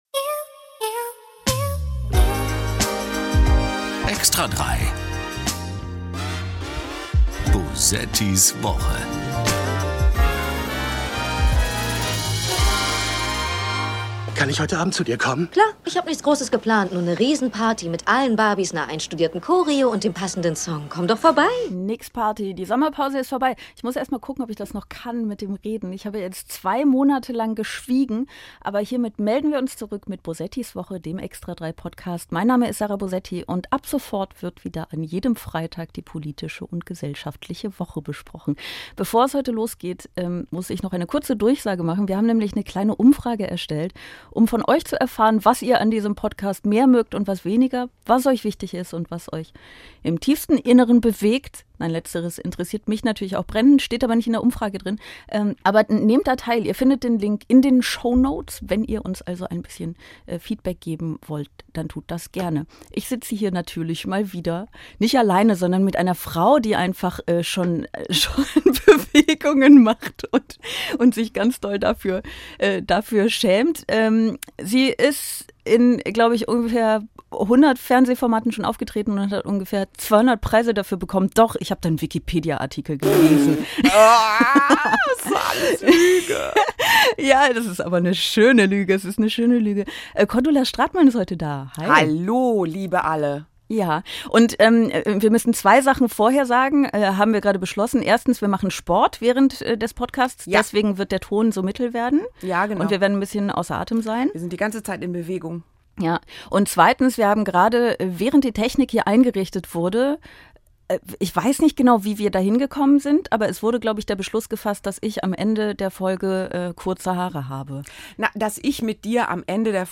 Die Komikerin und Schauspielerin spricht in der ersten Folge nach der Sommerpause mit Sarah Bosetti über den Piraten im Kanzleramt und natürlich über die Flugblattaffäre, auf die Cordula vor allem mit Ekel blickt.